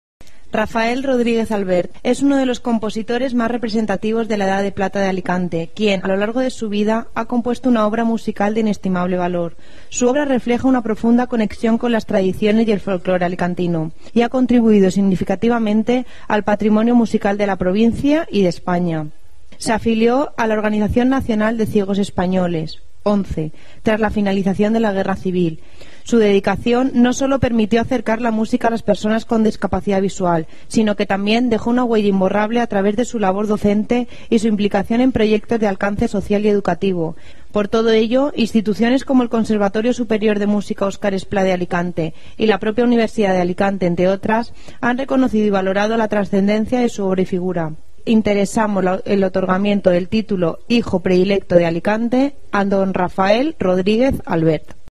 Edil de cultura, Nayma Beldjilali